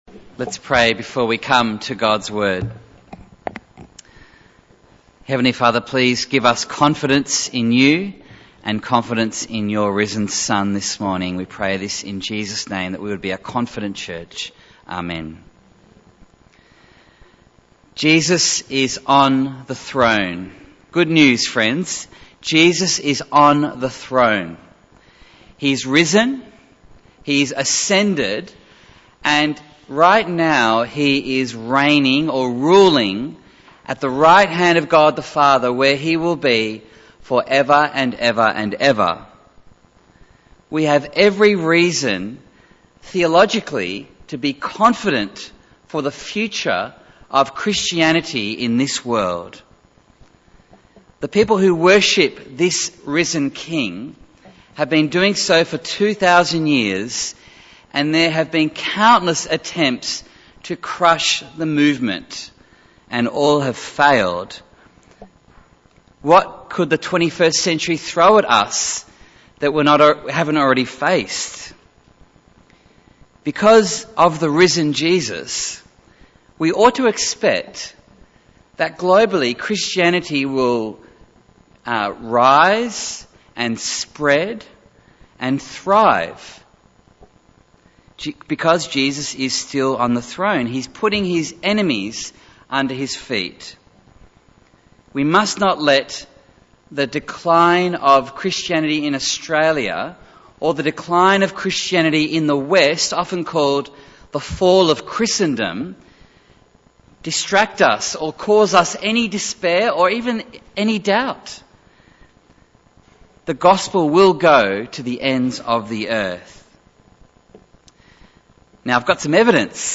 Bible Text: Luke 15:1-10 | Preacher